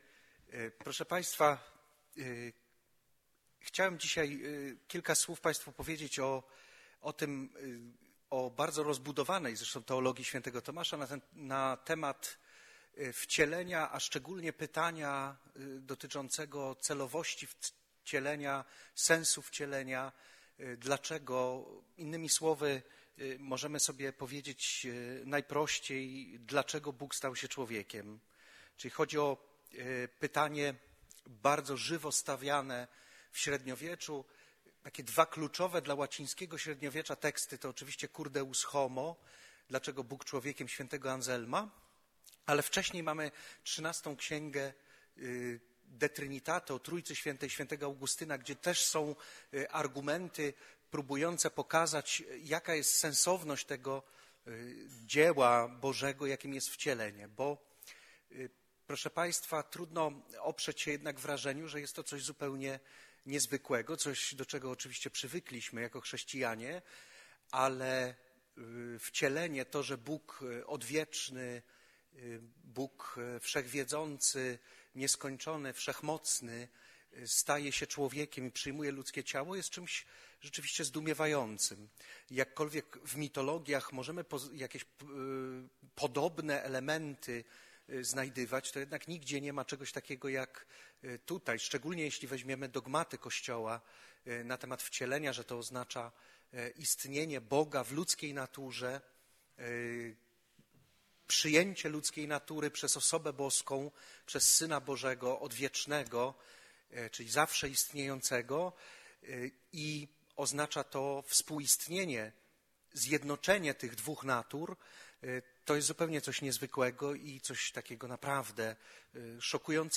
Wykłady Otwarte: O Wcieleniu – Instytut Tomistyczny